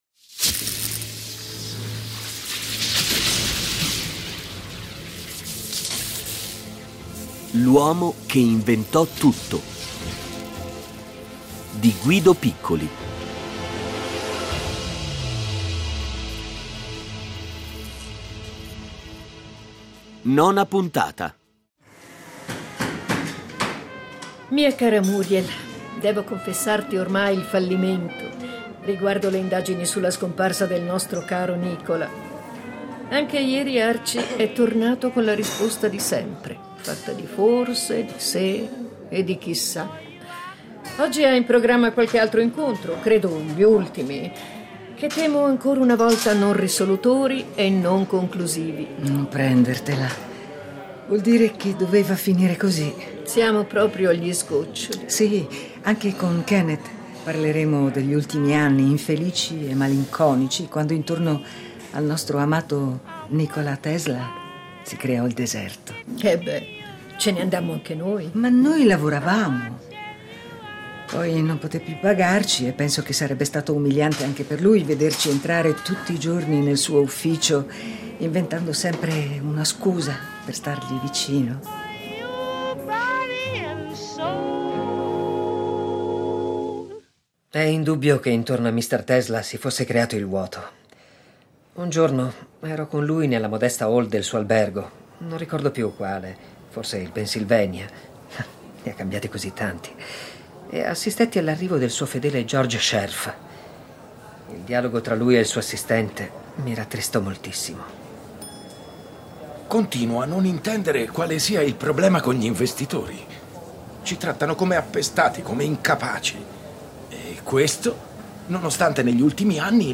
Originale radiofonico